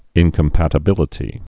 (ĭnkəm-pătə-bĭlĭ-tē)